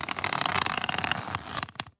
Leather Creak
Thick leather creaking under stress with deep, textured groaning and stretching
leather-creak.mp3